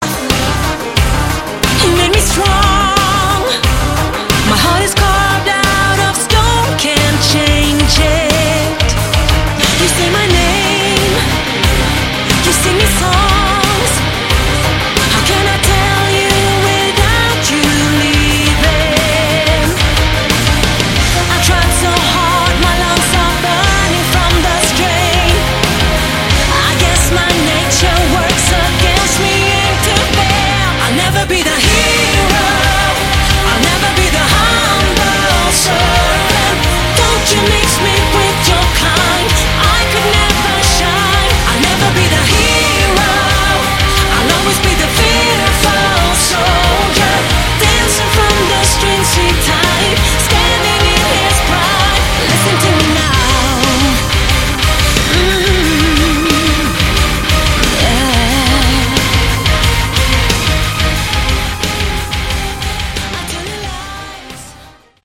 Category: Melodic Synmphonic Prog Metal
lead and background vocals
drums, keyboards
guitars
bass guitar